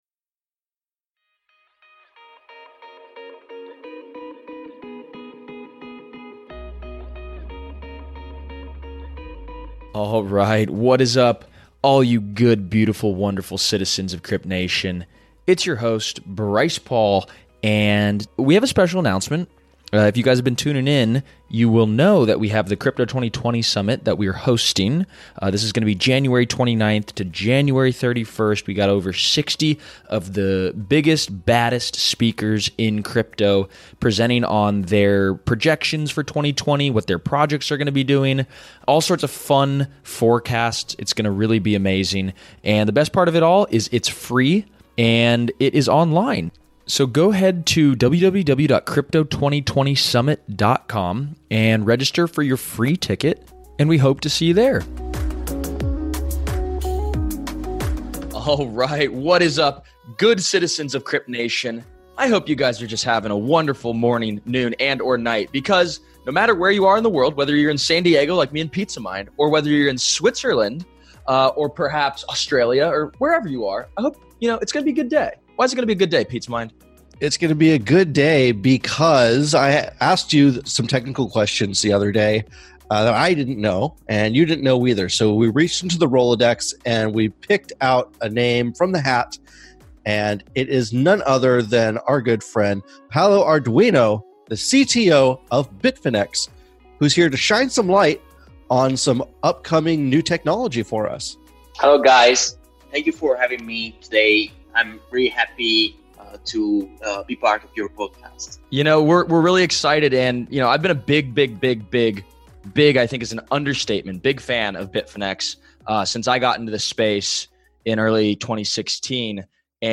We have a very future-facing conversation.